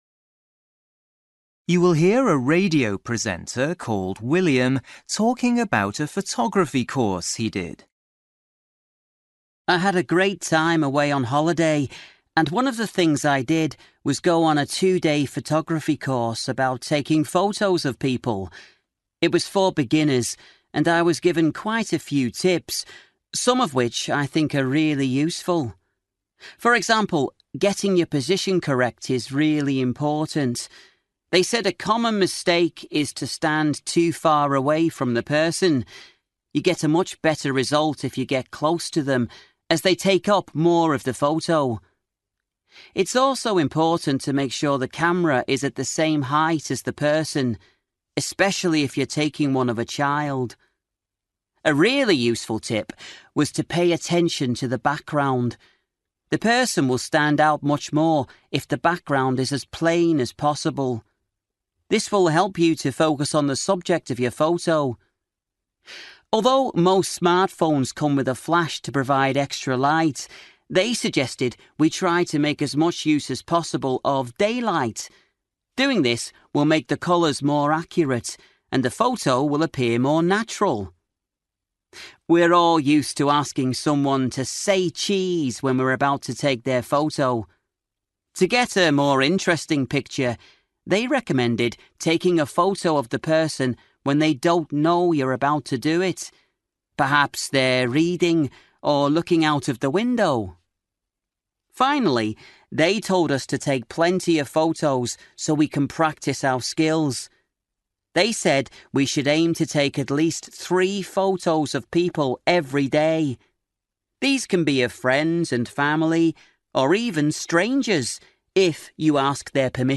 Luyện nghe trình độ B1